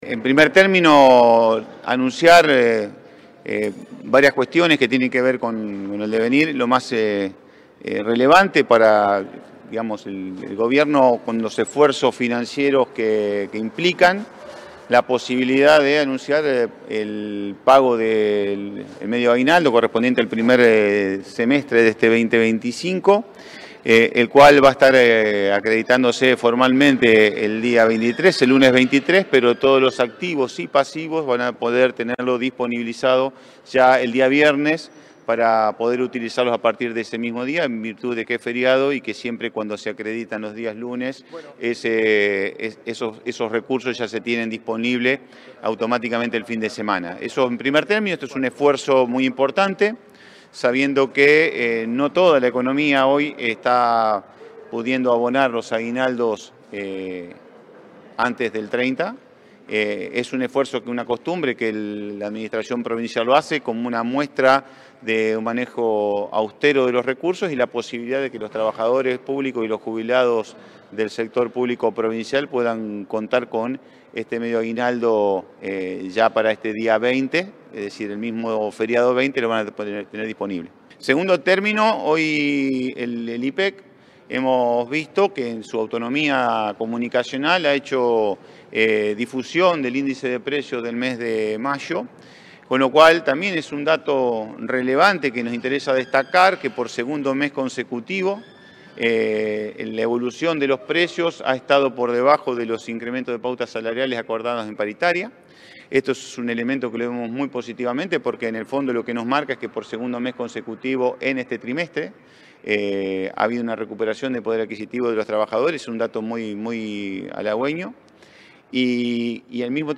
El ministro de Economía, Pablo Olivares, anunció en conferencia de prensa la fecha del pago del primer Sueldo Anual Complementario para los empleados provinciales, que se abonará el lunes 23 de junio, pero estará depositado en las cuentas el viernes 20.
Pablo Olivares, ministro de Economía